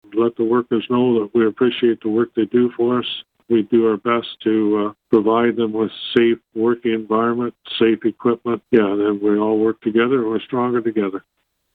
Mayor Jim Harrison says it is fantastic to have a contract in place with CUPE Local 799 city staff.